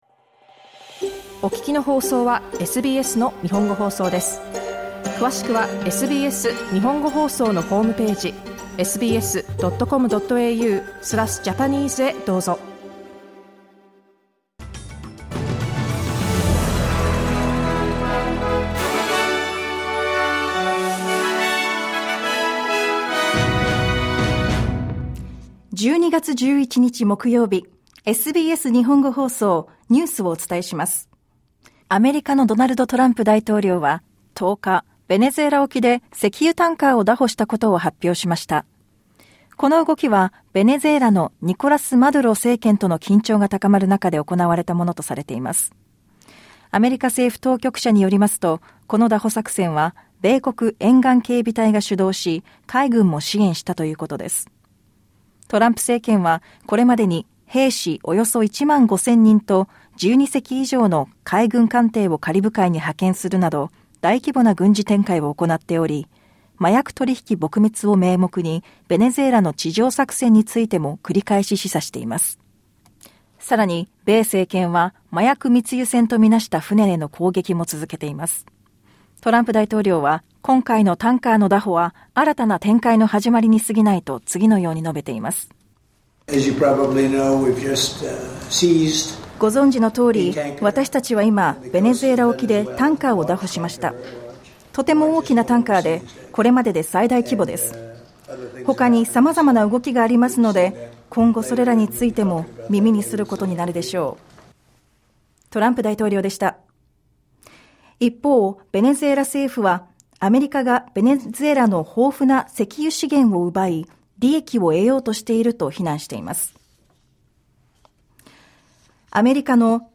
SBS日本語放送ニュース12月11日木曜日